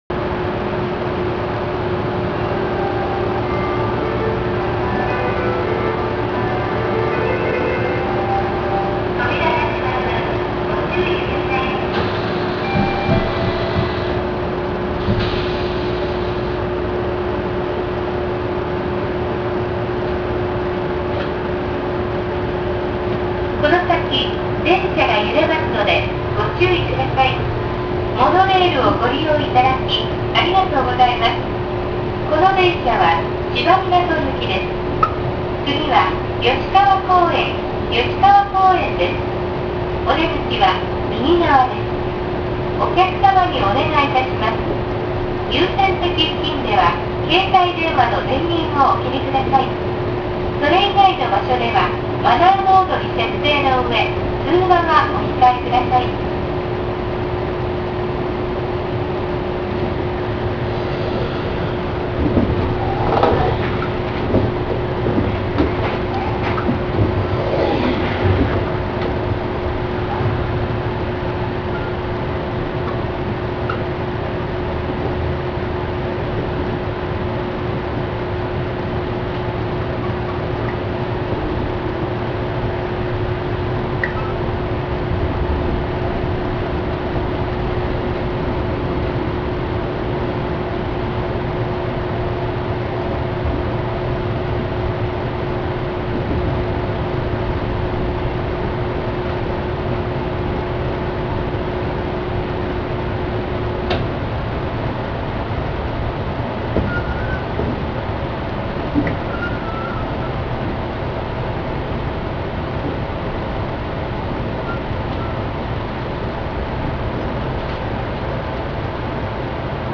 〜車両の音〜
・0形走行音
【１号線】県庁前→葭川公園（2分13秒：725KB）
走っているはずなのですが、全く音が聞こえず…。一応、三菱のIGBTの筈です。車内放送の声は東急や都営地下鉄、小田急などでよく聞ける声と同じです。ドアチャイムは東武のものに類似していますが、音程が低くなっています。